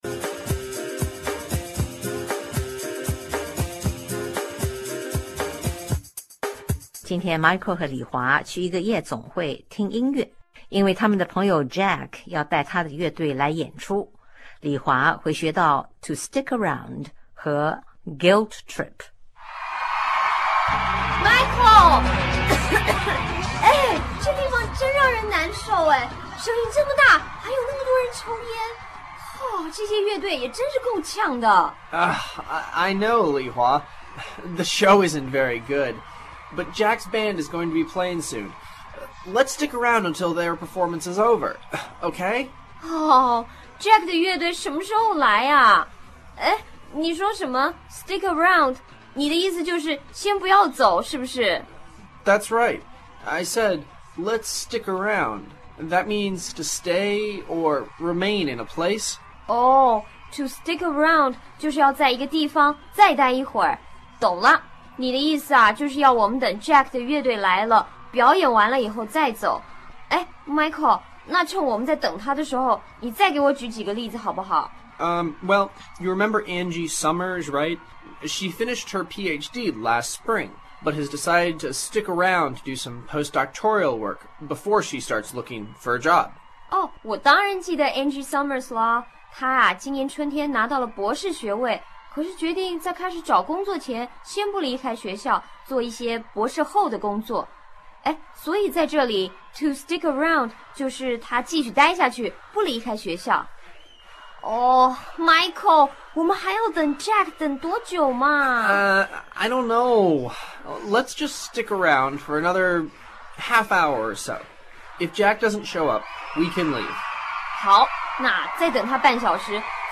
(noisy rock music playing, people talking, etc.)